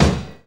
JAZZ KICK 2.wav